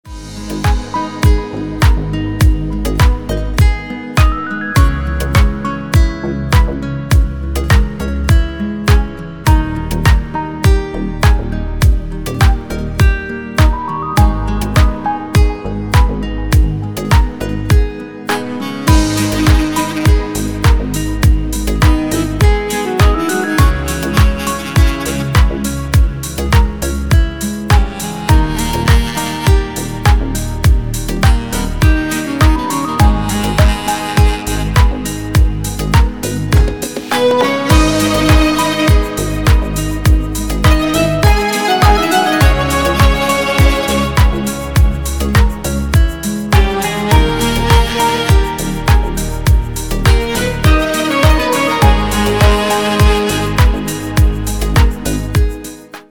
Рингтоны из спокойного музла